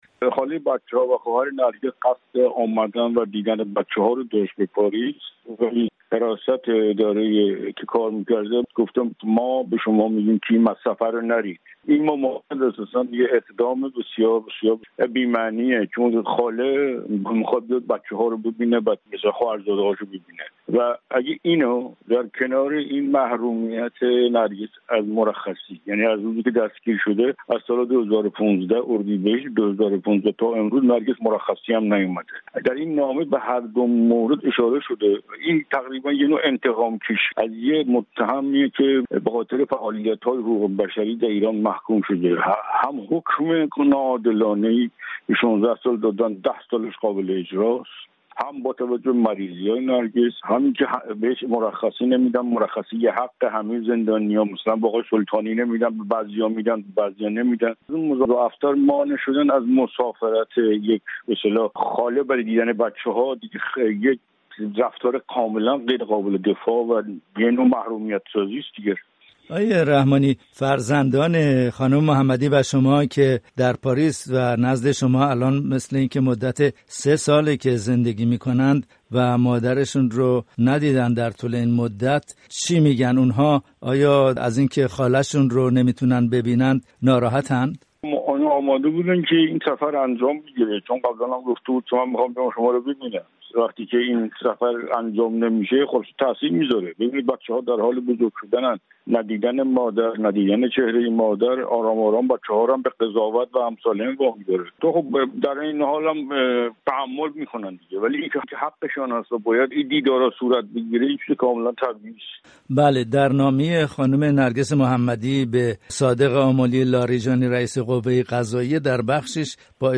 در گفت‌وگو با رادیوفردا